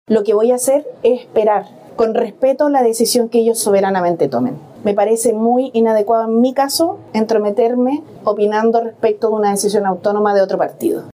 Mientras tanto, la candidata Jeannette Jara optó por marcar distancia de la discusión, afirmando que es respetuosa del proceso interno que lleva adelante la falange.